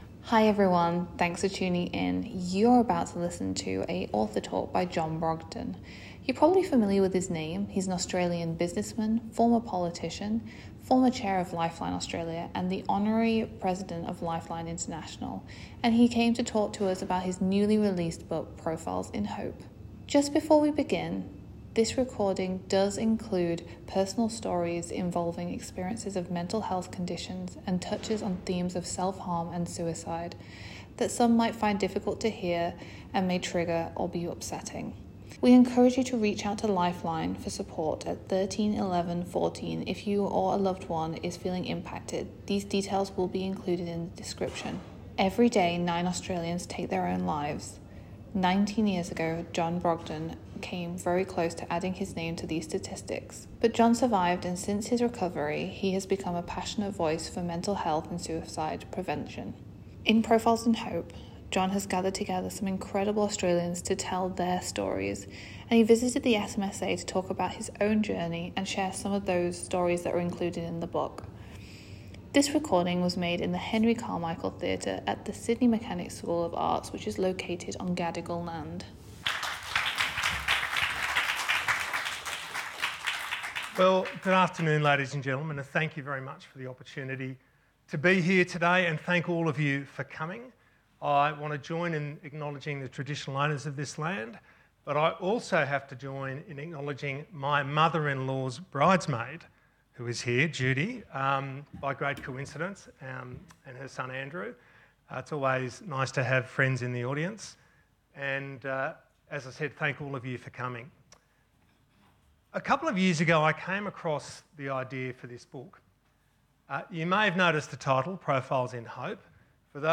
Listen Again: John Brogden Author Talk
John-Brogden-SMSA-Event-Recording.mp3